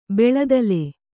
♪ beḷadale